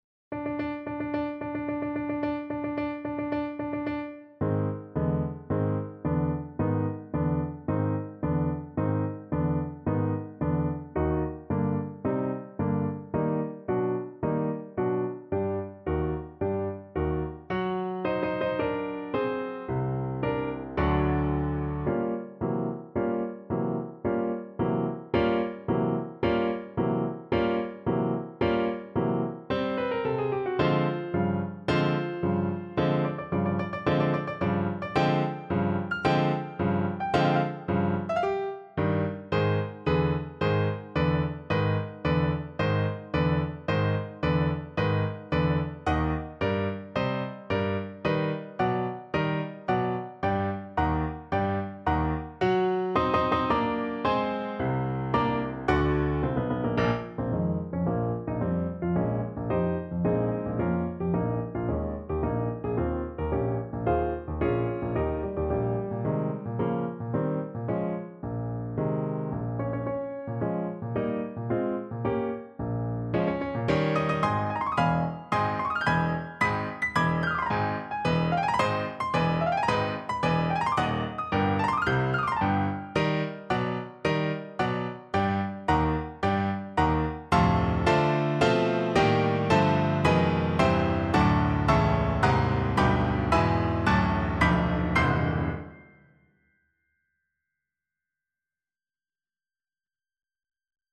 4/4 (View more 4/4 Music)
~ = 110 Tempo di Marcia
Classical (View more Classical Violin Music)